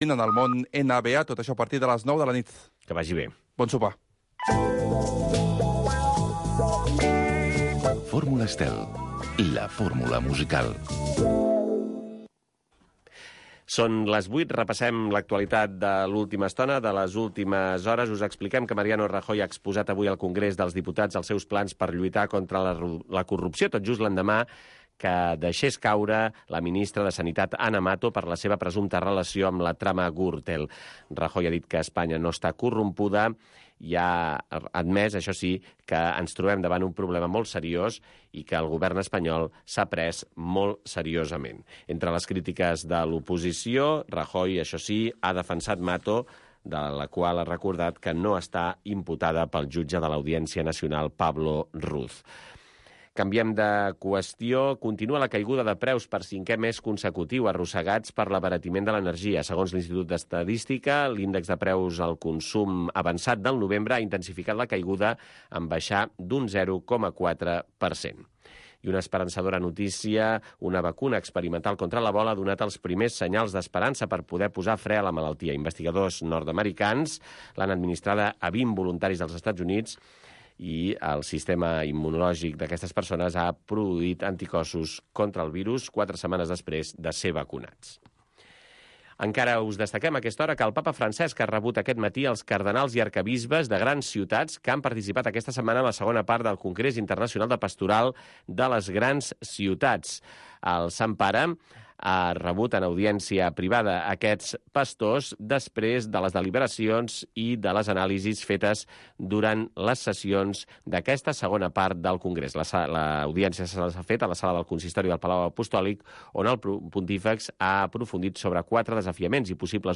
Plusvàlua. Un programa amb entrevistes i tertúlia sobre economia amb clau de valors humans, produït pel CEES